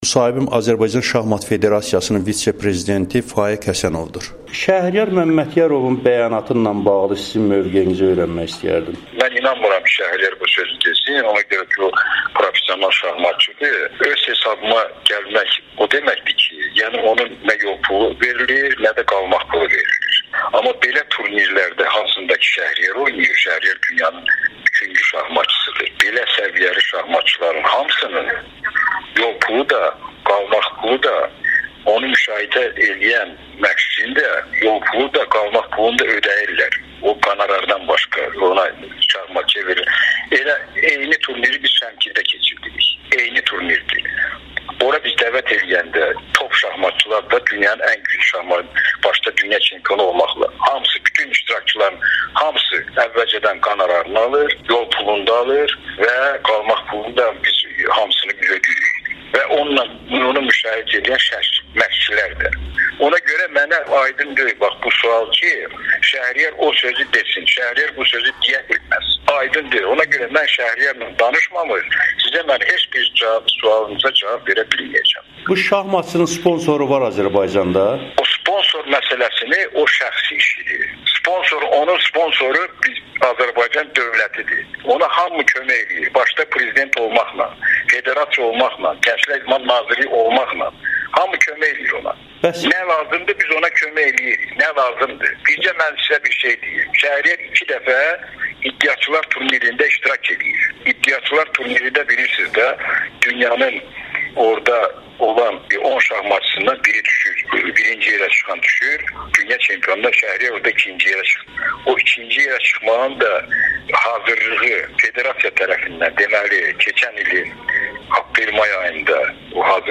Şəhriyar Məmmədyarovun sponsoru Azərbaycan dövlətidir [Audio-müsahibə]